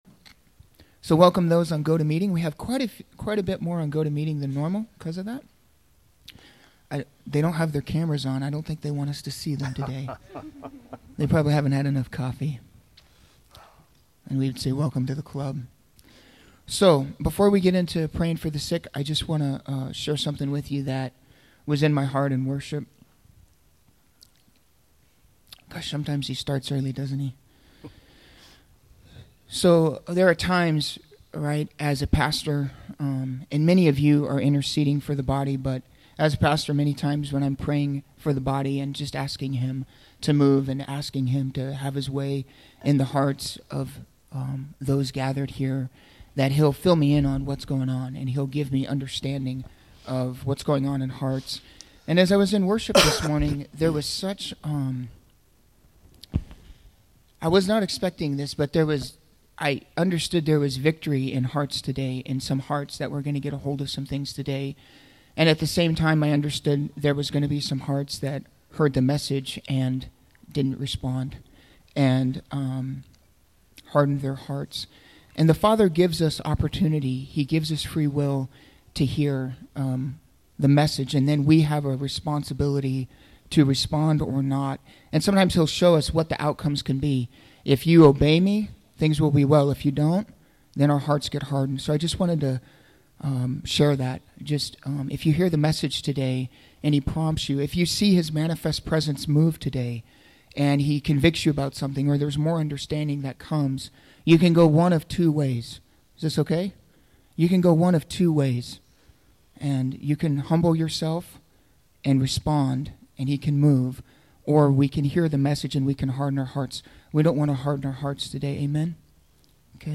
71 Share this sermon